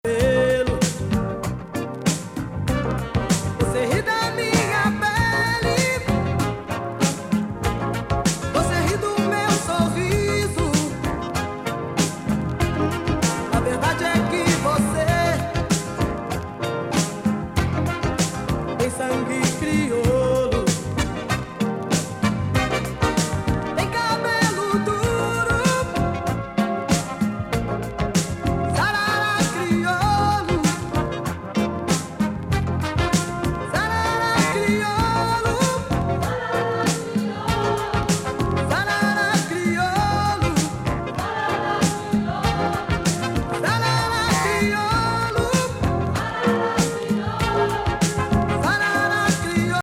ブラジリアン・モダンソウル
郷愁バレアリック